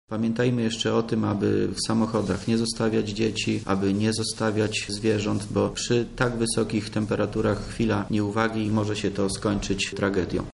Briefing u wojewody2